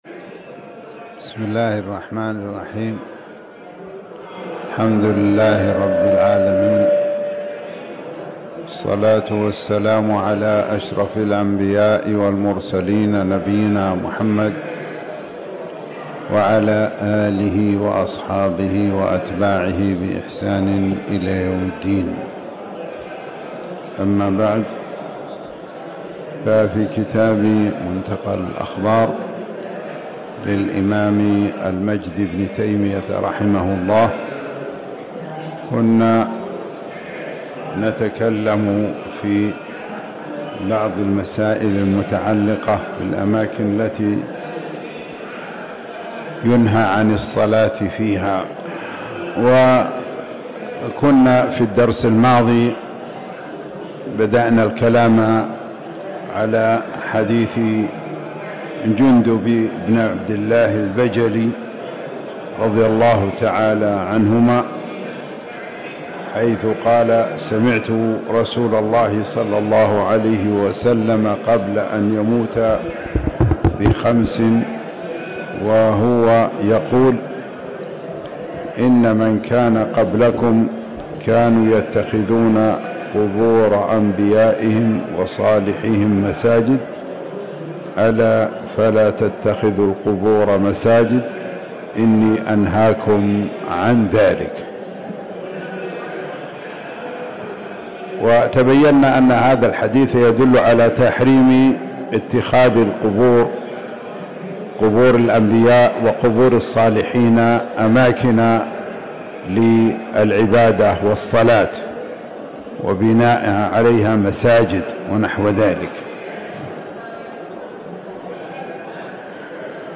تاريخ النشر ٢٨ جمادى الآخرة ١٤٤٠ هـ المكان: المسجد الحرام الشيخ